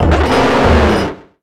Cri de Bétochef dans Pokémon X et Y.